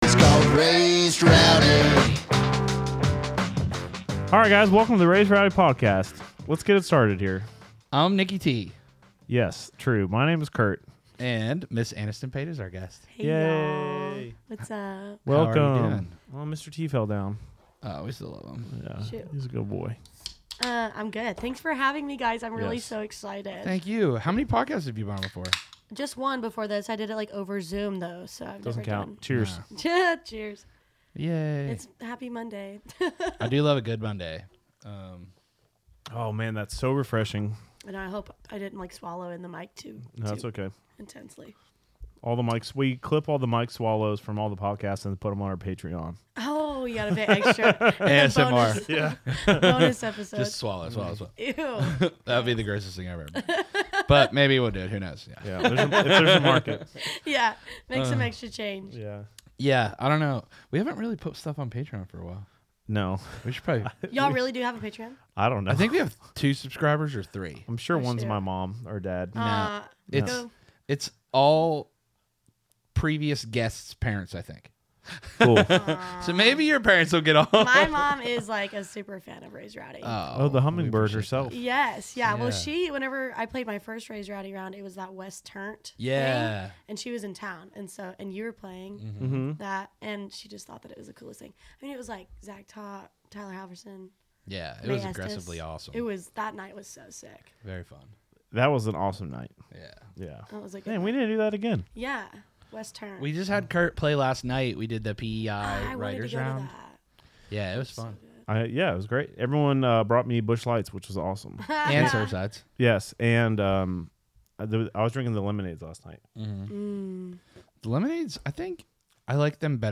a lively discussion about her musical journey, influences, and experiences in the Nashville music scene. They explore the evolving landscape for female artists in country music, the importance of networking during college, and the vibrant honky tonk culture that shapes Nashville.